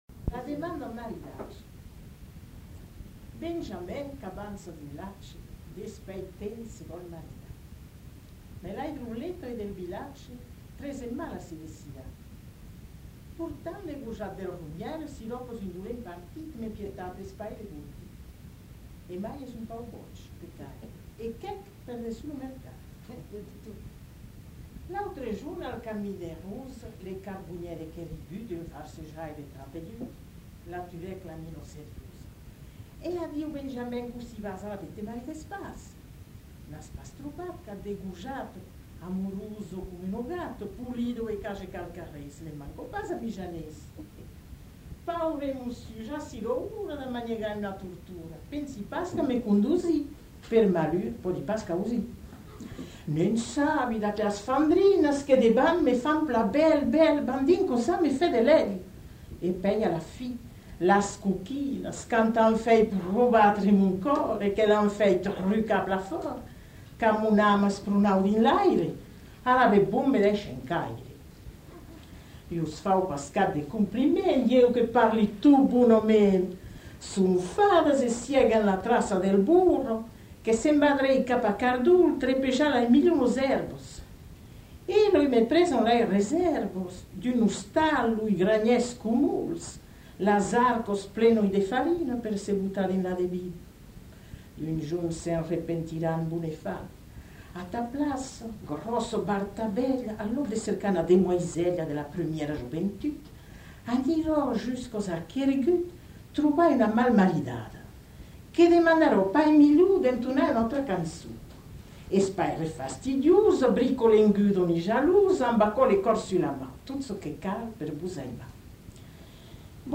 Aire culturelle : Couserans
Lieu : Prat-Communal (lieu-dit)
Genre : conte-légende-récit
Effectif : 1
Type de voix : voix de femme
Production du son : récité
Classification : monologue